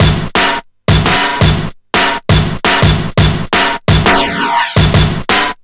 Index of /90_sSampleCDs/Zero-G - Total Drum Bass/Drumloops - 3/track 55 (170bpm)